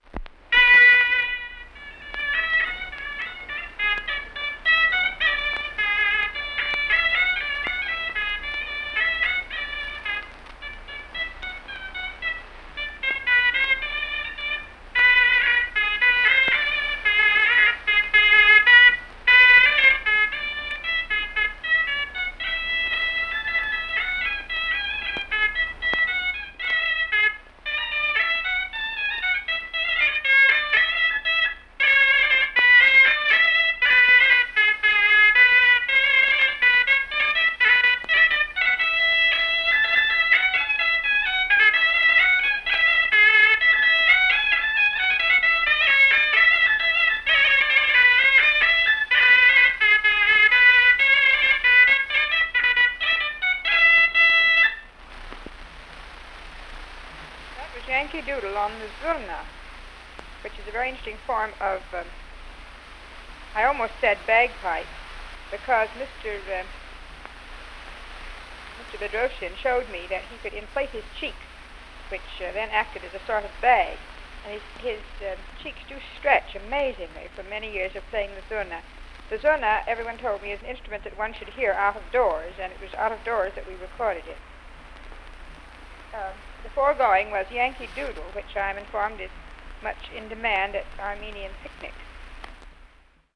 使用嗩吶吹奏亞美尼亞音樂的男人
吹奏樂器 (Wind Instruments)